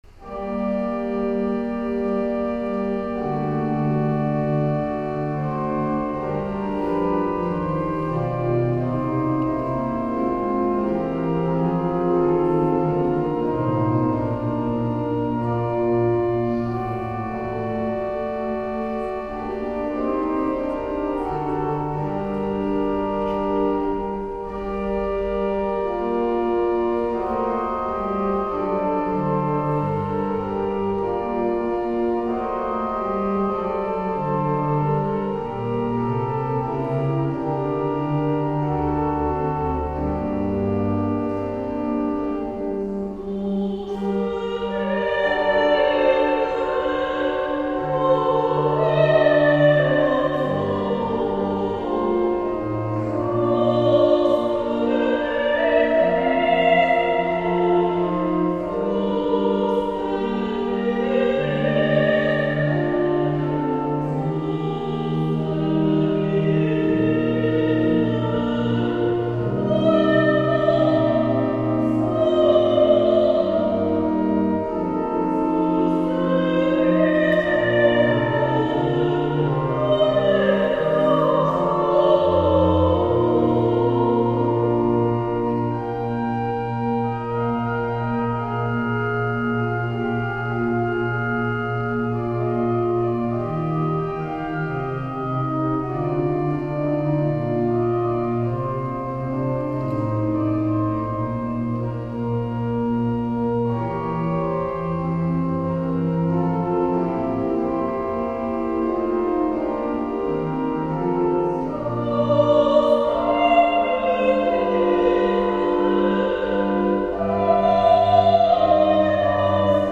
Posłuchaj mnie - utwory wykonane z towarzyszeniem organów